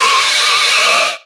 Grito de Whirlipede.ogg
Grito_de_Whirlipede.ogg.mp3